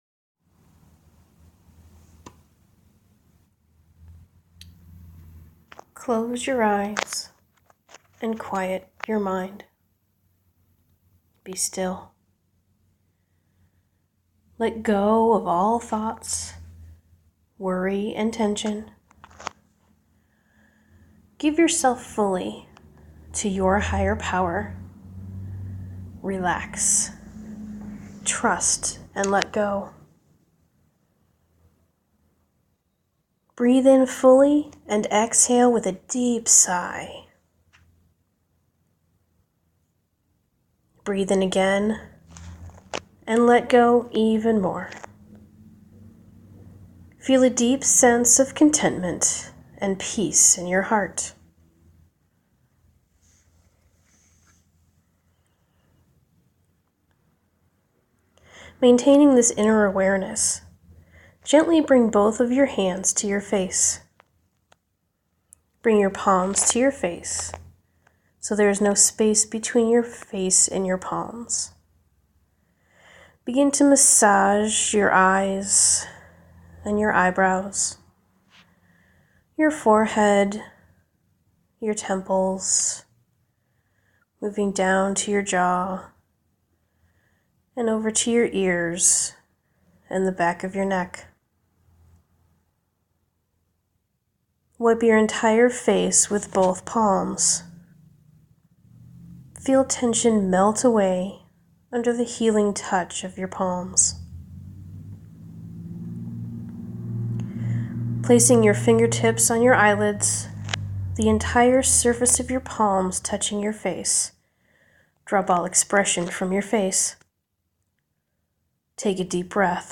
Relax with Yoga Nidra guided meditation – Soul Armour
This is a guided meditation that absolutely anyone can do. There are no poses involved, just find a comfortable place to lay down.
15-min-nidra.m4a